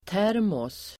Ladda ner uttalet
Uttal: [t'är:mås]